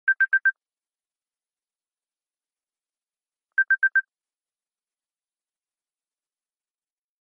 Darmowe dzwonki - kategoria SMS
Dzwonek - Sygnał
Krótki i cykliczny z długim odstępem dźwięk dla sygnału.
sygnal.mp3